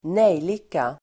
Ladda ner uttalet
Uttal: [²n'ej:lika]
nejlika.mp3